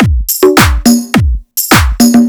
105 BPM Beat Loops Download